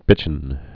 (bĭchən)